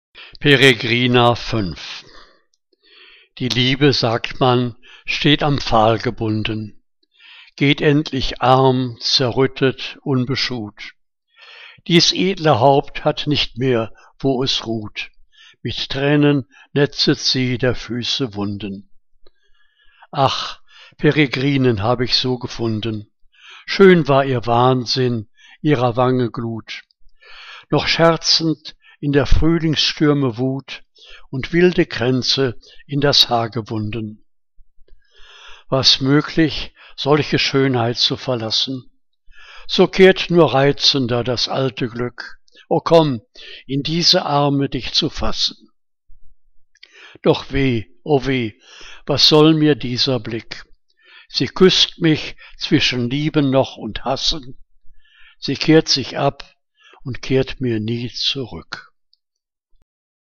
Liebeslyrik deutscher Dichter und Dichterinnen - gesprochen (Eduard Mörike)